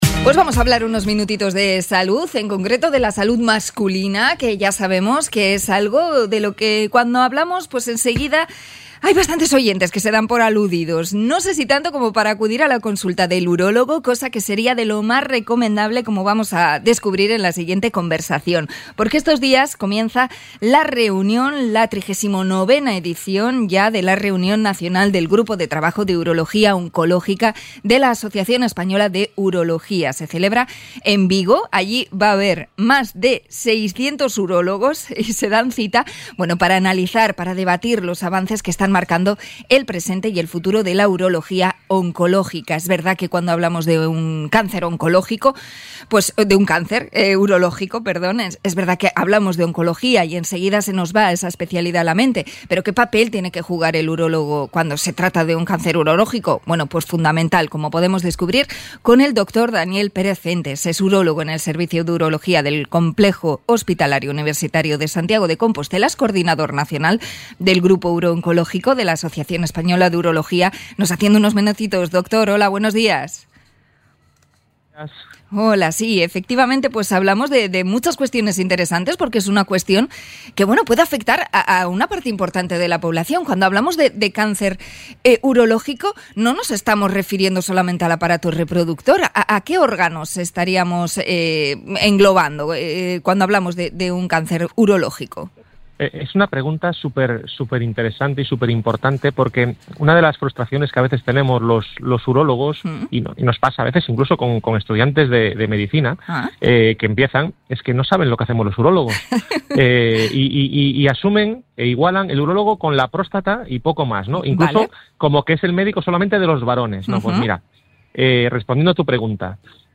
Entrevista por el congreso de urólogos en Vigo